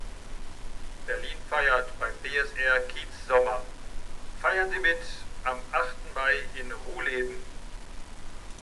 Die Besucher wurden von den Stimmen aus den Mülleimern begrüßt und eingestimmt.